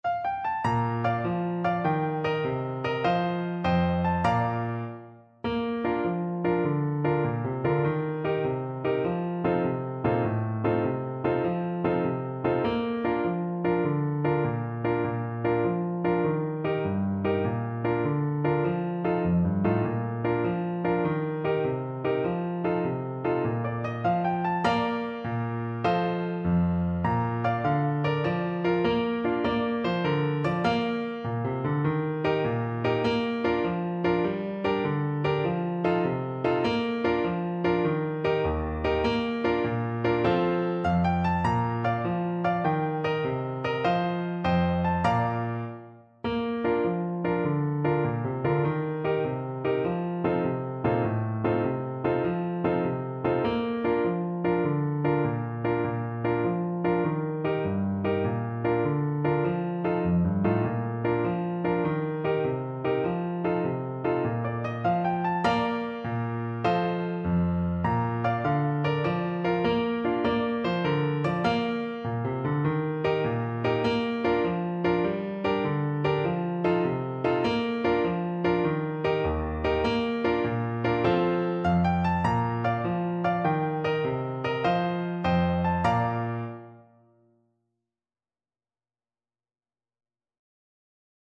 Clarinet
6/8 (View more 6/8 Music)
Allegro .=c.100 (View more music marked Allegro)
Traditional (View more Traditional Clarinet Music)